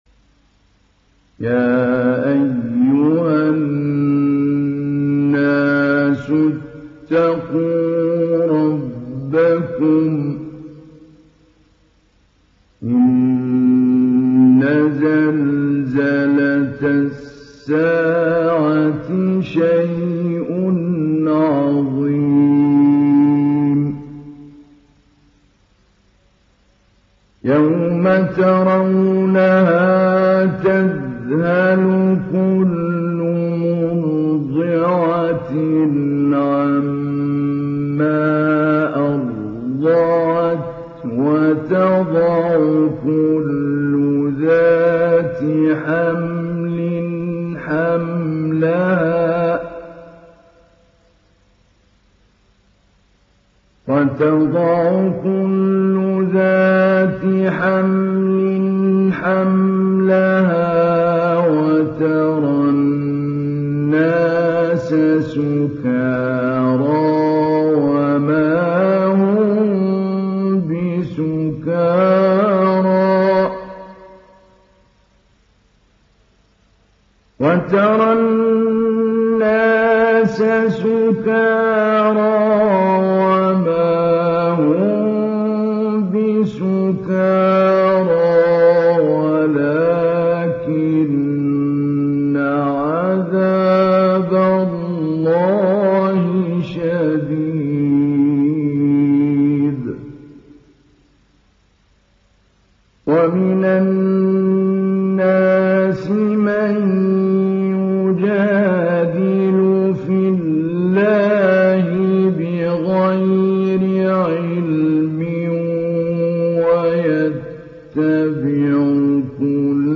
Surah Al Haj Download mp3 Mahmoud Ali Albanna Mujawwad Riwayat Hafs from Asim, Download Quran and listen mp3 full direct links
Download Surah Al Haj Mahmoud Ali Albanna Mujawwad